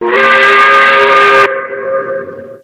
mvm_bomb_warning.wav